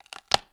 SNAP_Clean_mono.wav